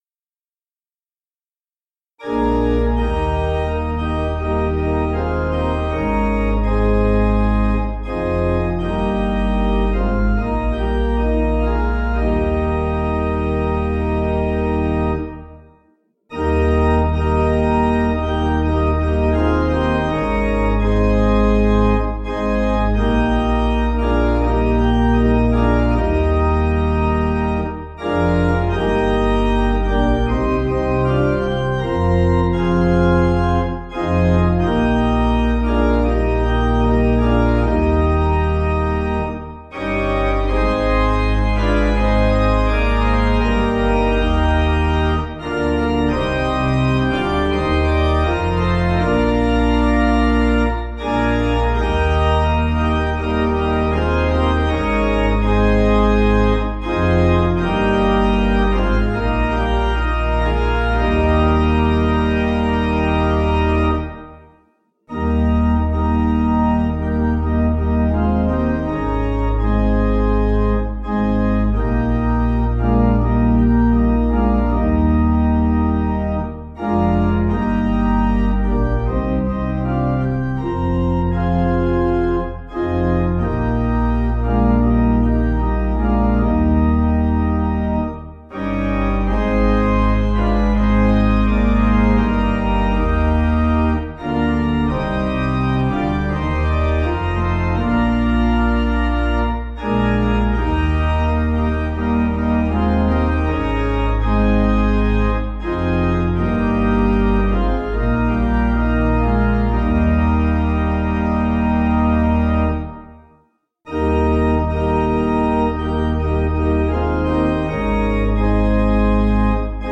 Organ
(CM)   4/Eb 496.9kb